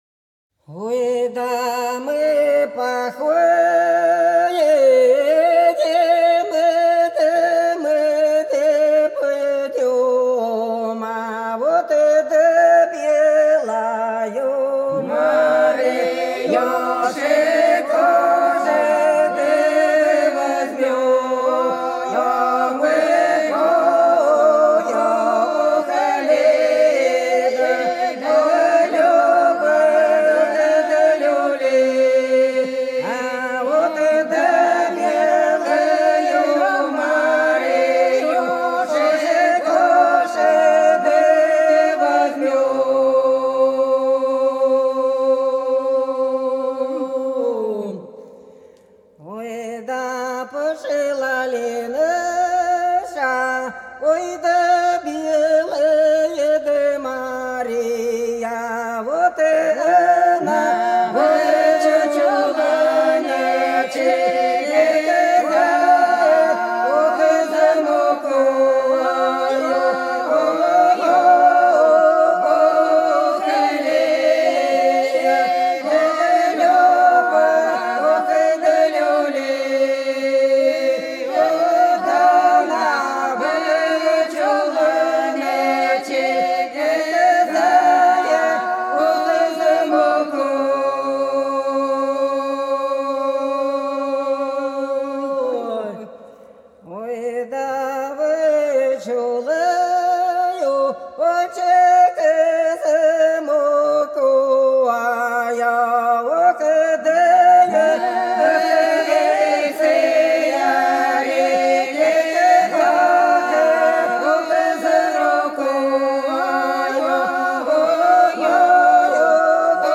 01 Хороводная постовая песня «Мы походим, мы пойдём» в исполнении жителей с. Прудки Красногвардейского р-на Белгородской обл.